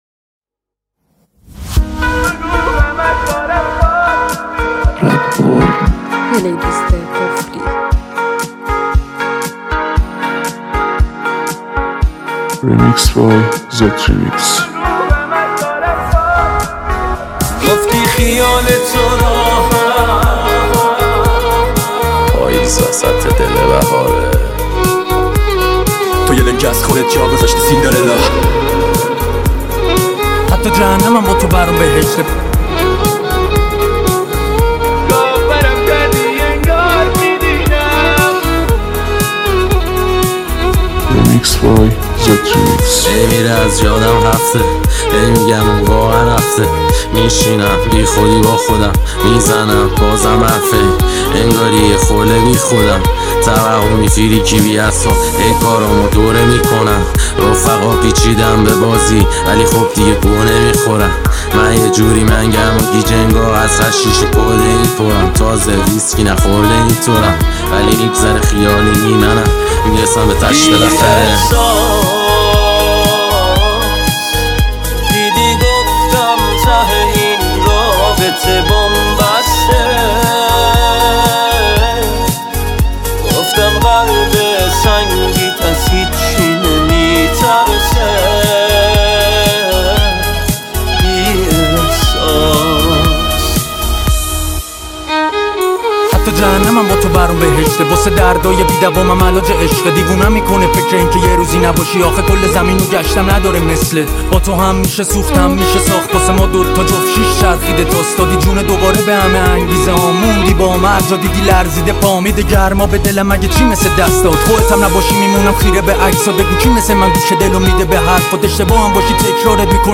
بیت گذاری